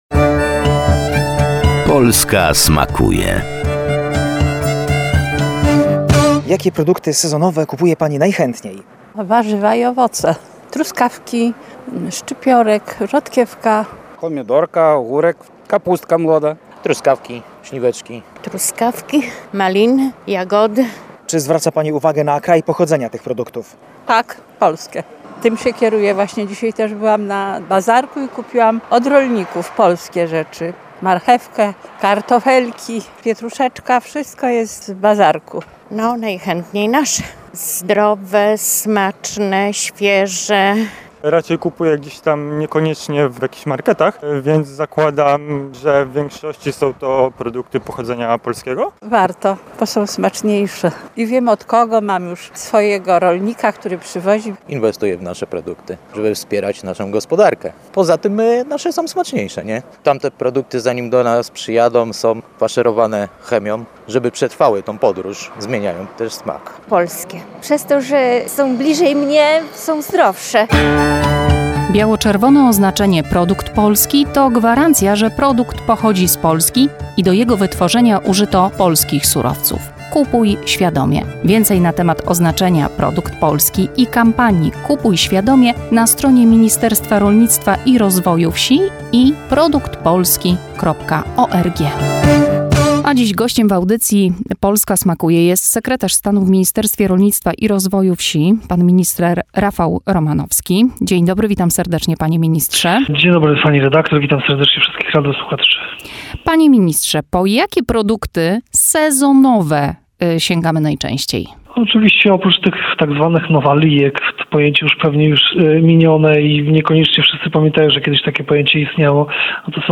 W audycji został poruszony temat produktów sezonowych oraz patriotyzmu konsumenckiego.